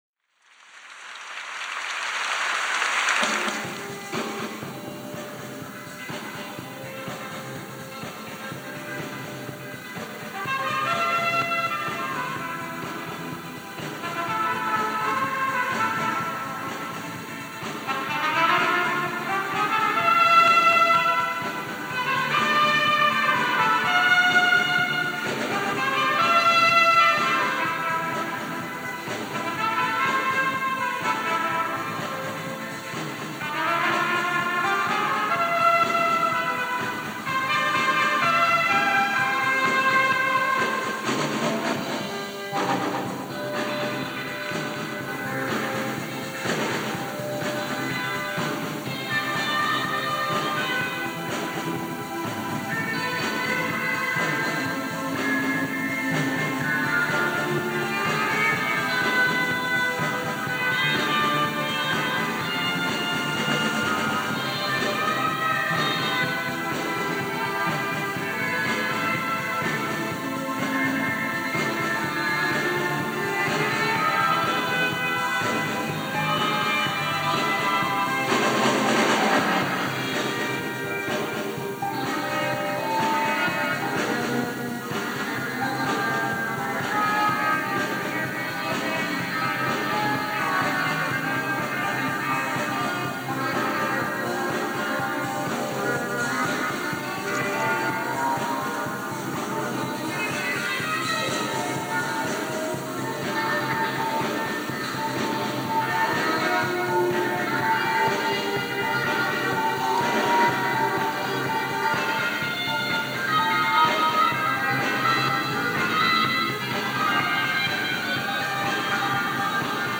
それにしても楽器が良くなっていること！